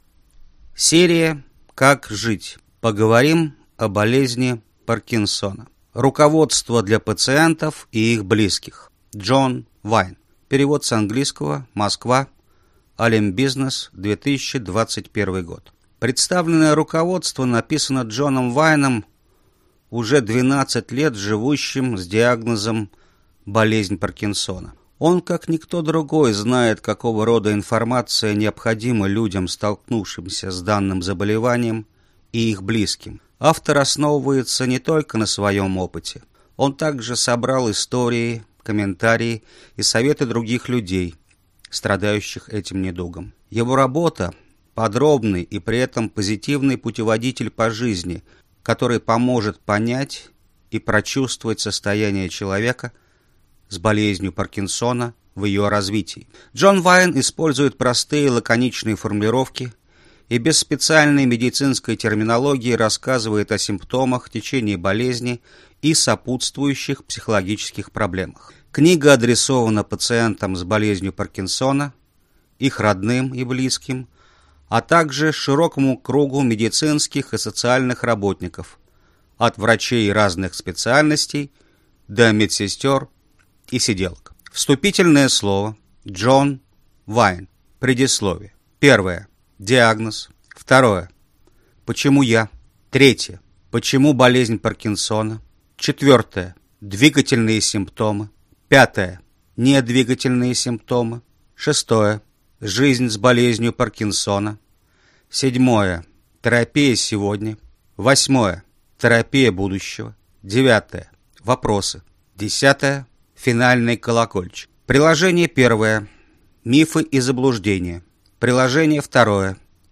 Аудиокнига Поговорим о болезни Паркинсона | Библиотека аудиокниг